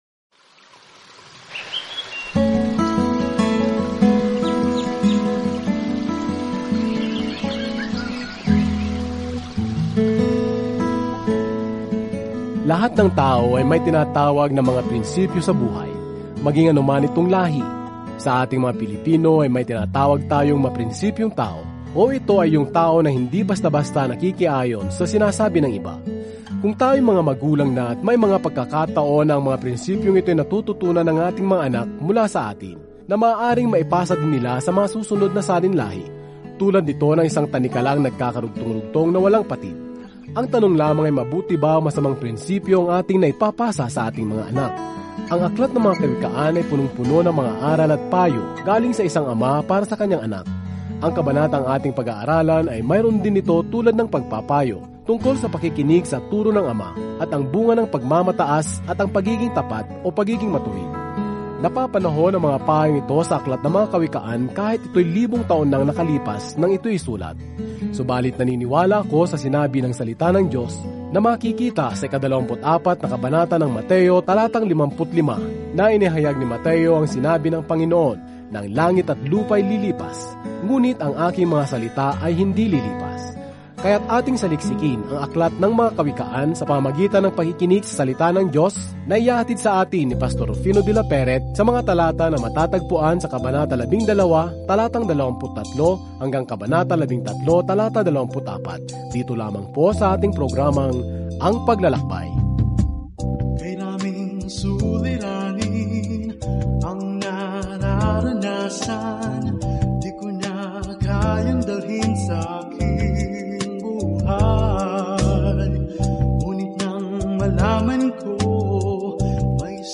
Araw-araw na paglalakbay sa Mga Kawikaan habang nakikinig ka sa audio study at nagbabasa ng mga piling talata mula sa salita ng Diyos.